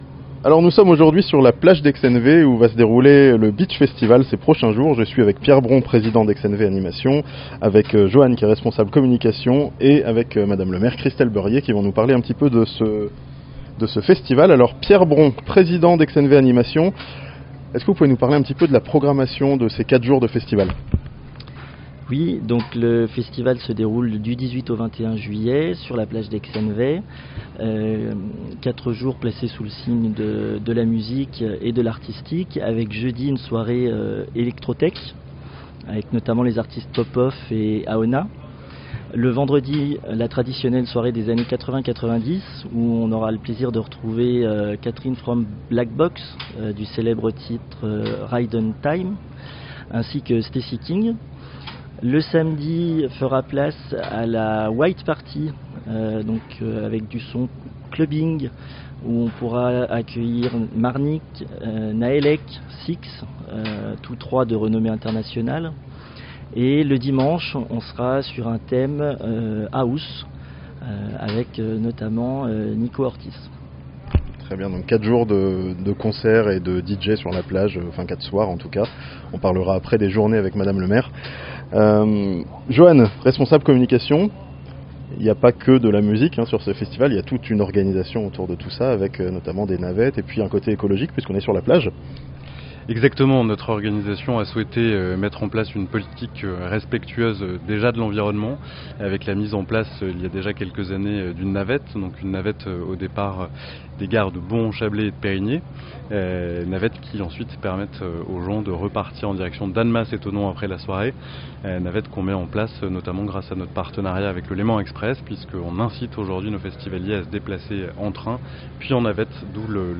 A Excenevex, le Beach Festival prend place pour 4 jours sur la plage (interview)